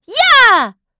Estelle-Vox_Attack4.wav